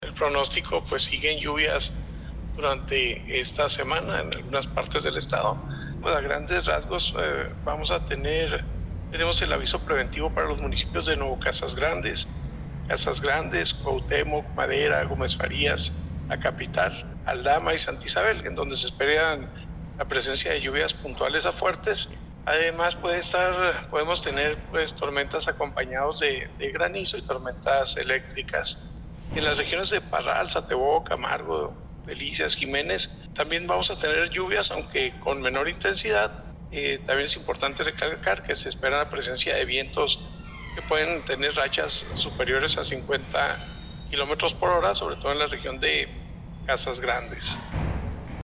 AUDIO: LUIS CORRAL TORRESDEY, TITULAR DE LA COORDINACIÓN ESTATAL DE PROTECCIÓN CIVIL DE CHIHUAHUA